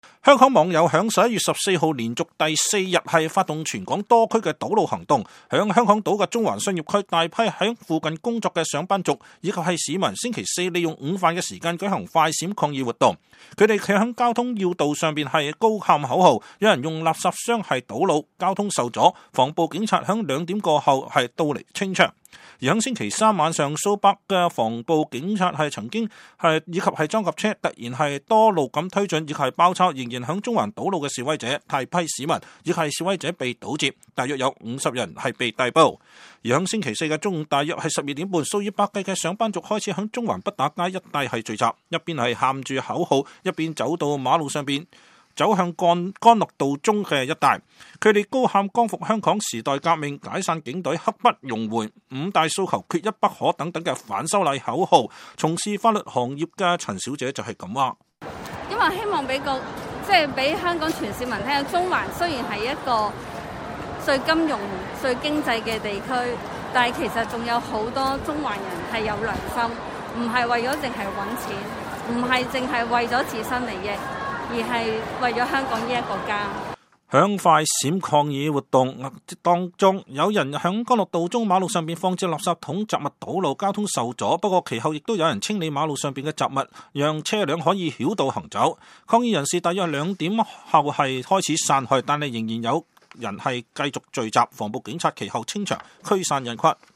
他們站在交通要道上高喊口號，有人用垃圾箱堵路，交通受阻。